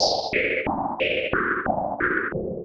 RI_RhythNoise_90-01.wav